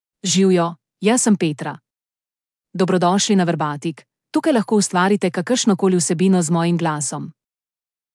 Petra — Female Slovenian (Slovenia) AI Voice | TTS, Voice Cloning & Video | Verbatik AI
Petra is a female AI voice for Slovenian (Slovenia).
Voice sample
Listen to Petra's female Slovenian voice.
Female